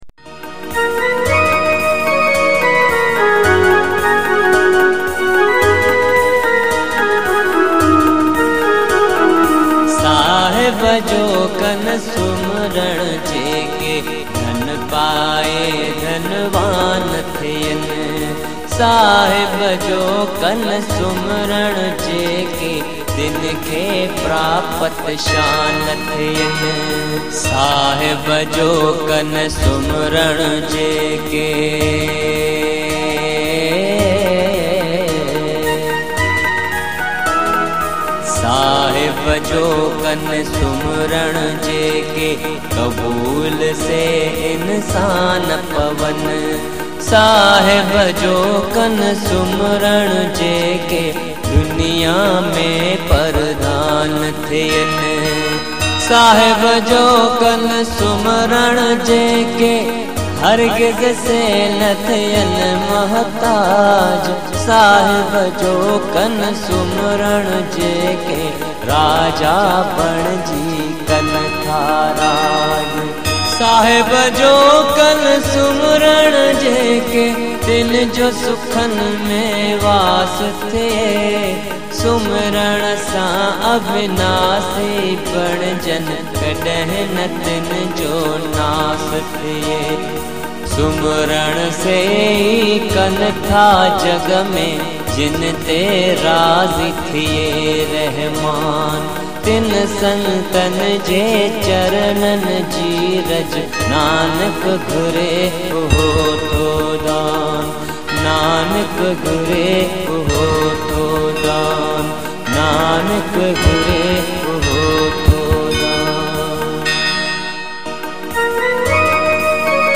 An Authentic Ever Green Sindhi Song Collection